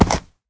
sounds / mob / horse / jump.ogg
jump.ogg